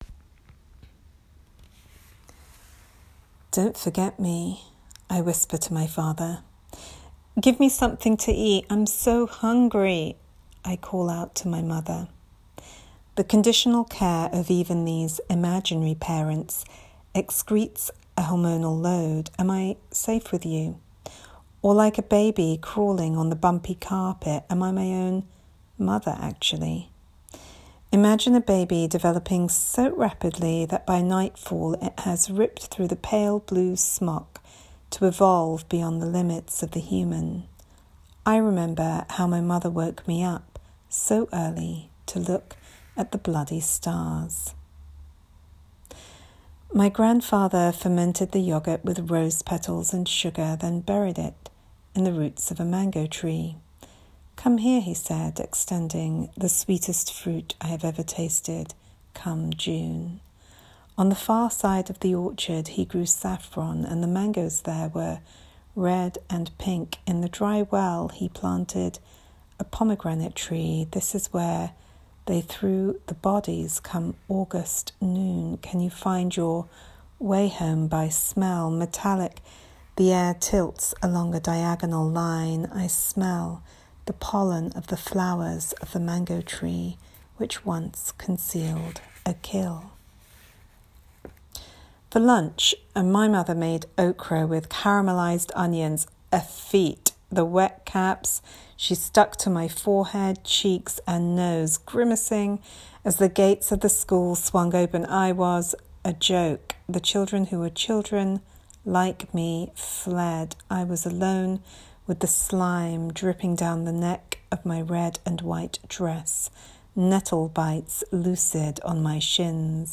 Pavilion Poetry Readings
In this audio clip, Bhanu Kapil reads from How to Wash a Heart (Pavilion Poetry, 2020).
churchill-college-grounds-storeys-way-3.m4a